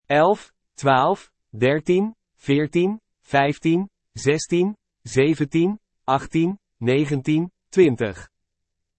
The tens from 1 to 100 are pronounced: